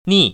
[nì] 니